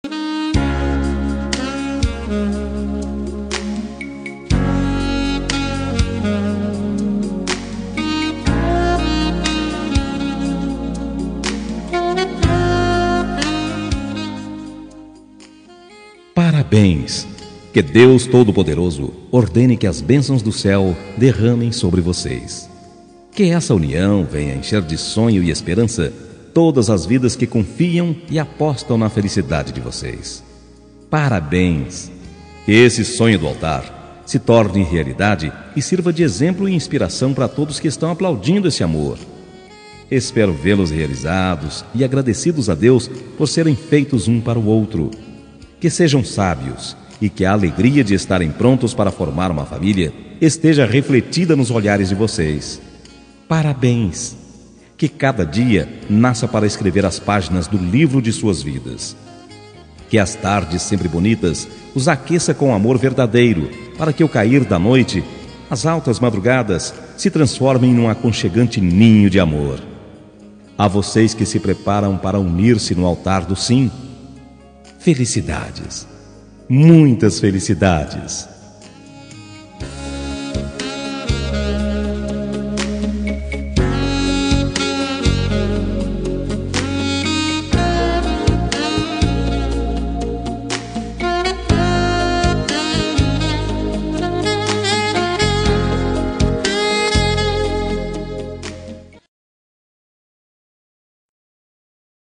Homenagem aos Noivos – Voz Masculina – Cód: 327